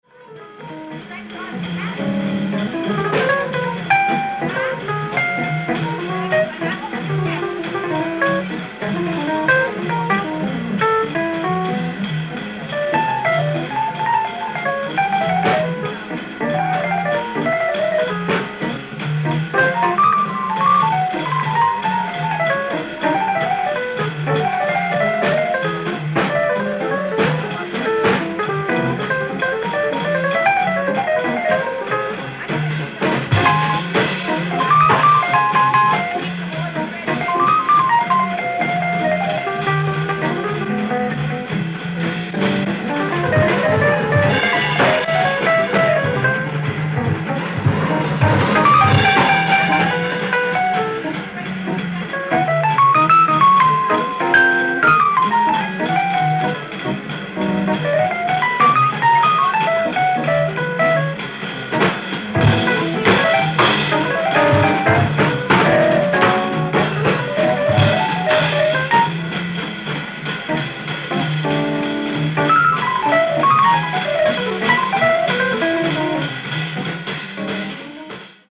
ライブ・アット・クラブ・ブルーコルネット、ブルックリン、ニューヨーク 1969